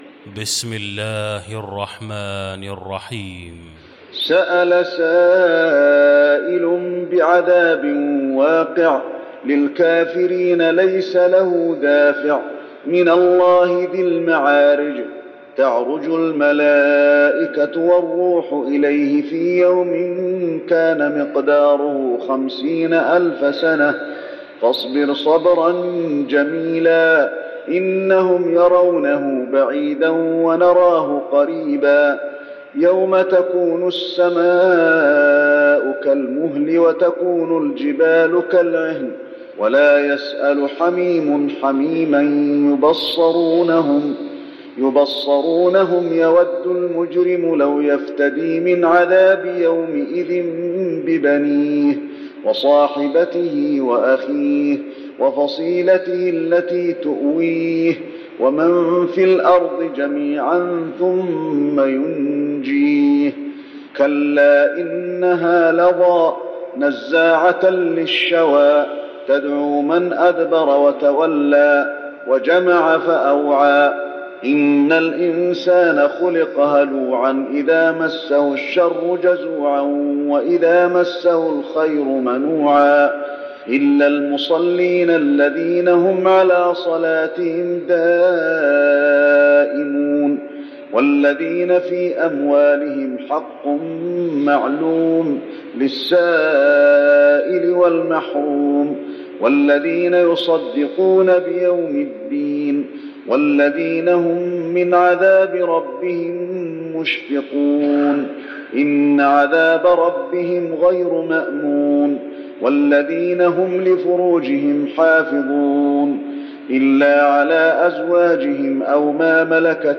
المكان: المسجد النبوي المعارج